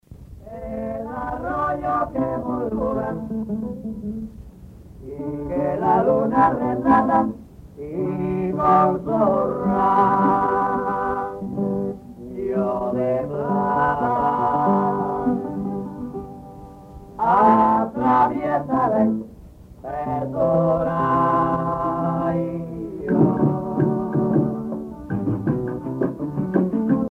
Parranda
Sancti Spiritus, Cuba
Pièce musicale inédite